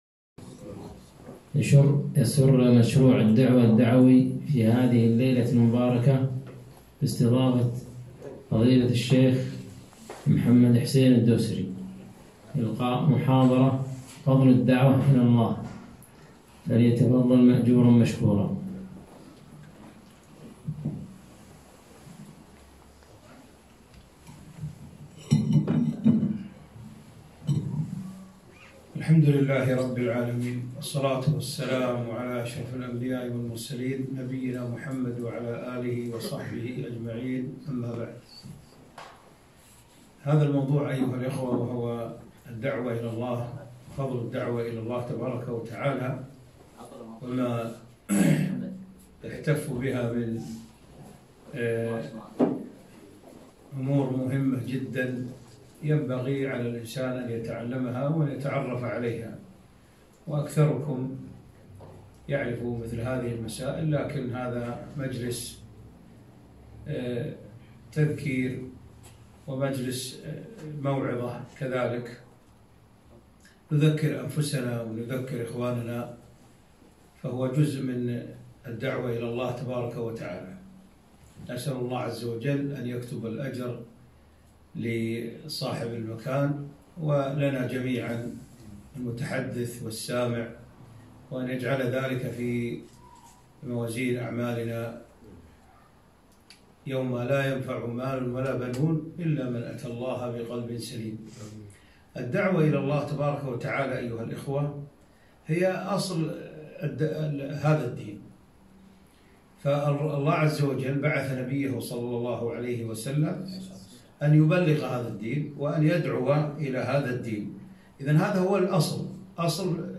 محاضرة - فضل الدعوة إلى الله